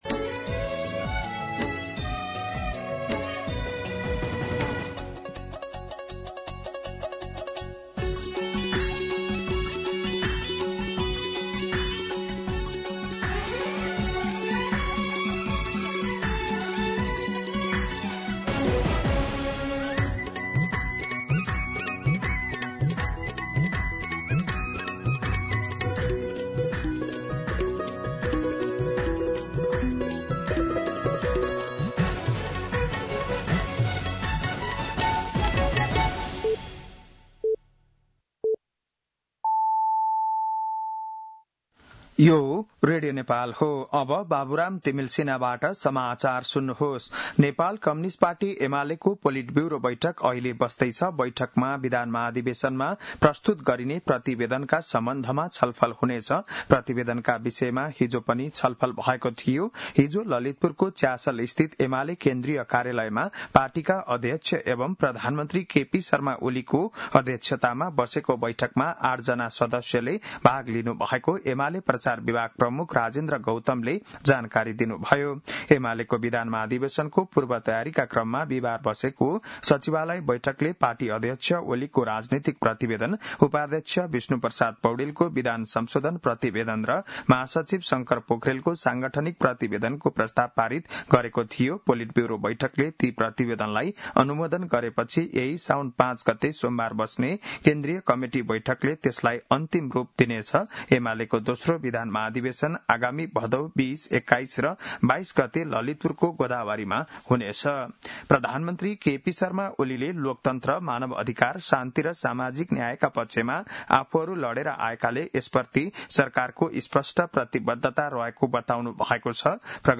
बिहान ११ बजेको नेपाली समाचार : ३ साउन , २०८२
11-am-Nepali-News-1.mp3